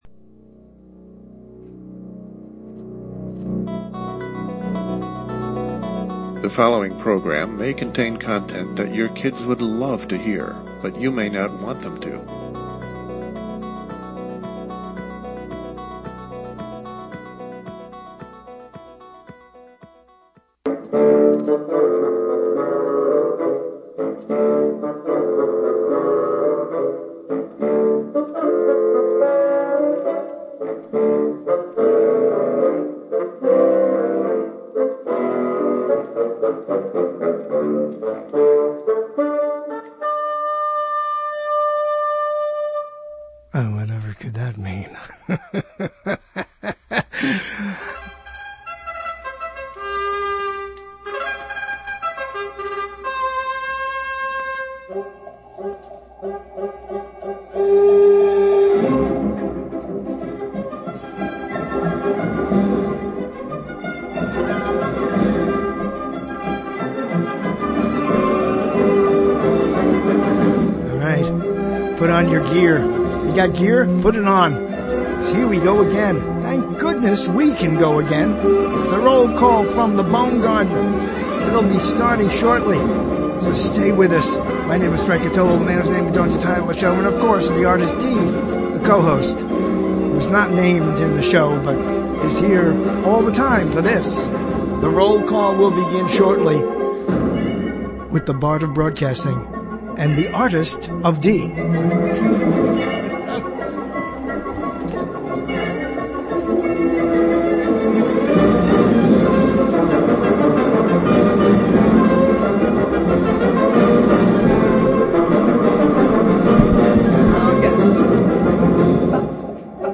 The internet’s original talk show continues in its original time slot with unmatched energy in the realm of broadcasting it created.